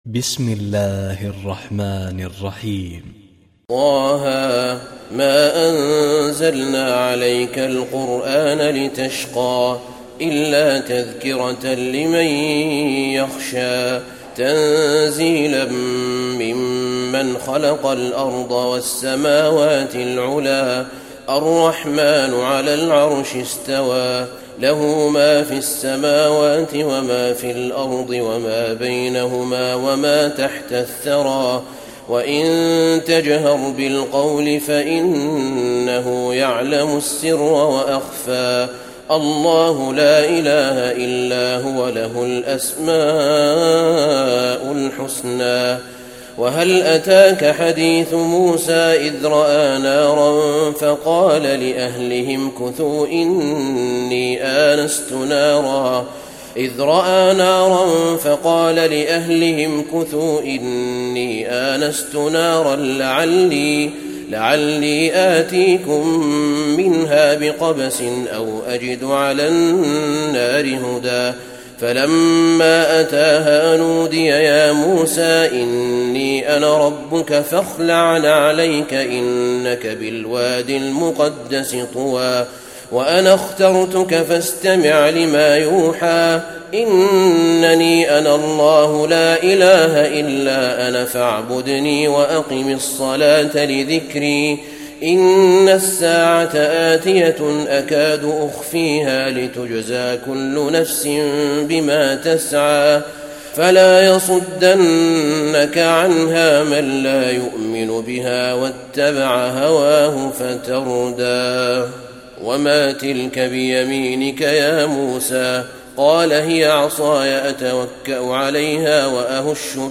تراويح الليلة الخامسة عشر رمضان 1435هـ سورة طه كاملة Taraweeh 15 st night Ramadan 1435H from Surah Taa-Haa > تراويح الحرم النبوي عام 1435 🕌 > التراويح - تلاوات الحرمين